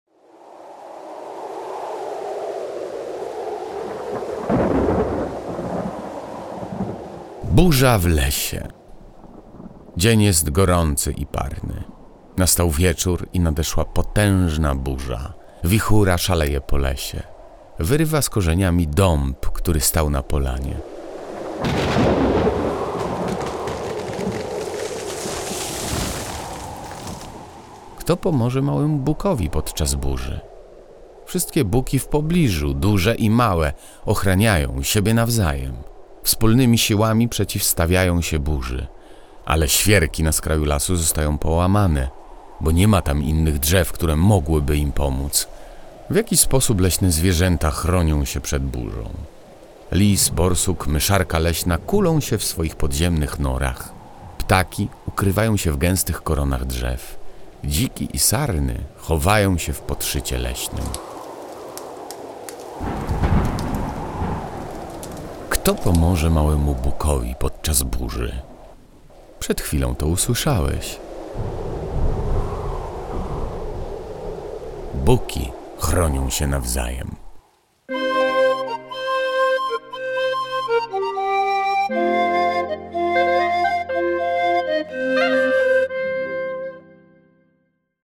Na dołączonej do książki płycie CD i pod kodem QR znajduje się interesująco opowiedziana historia, której towarzyszą odgłosy przyrody.
8_Burza_w_lesie.mp3